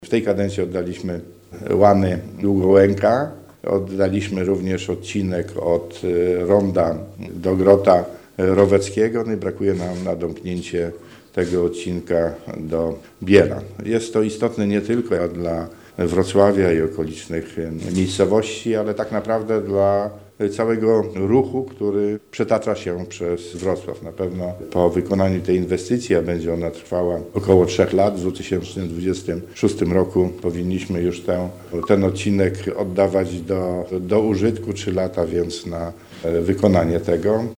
Domykamy pierścień wokół Wrocławia, zaznacza Cezary Przybylski, Marszałek Województwa Dolnośląskiego.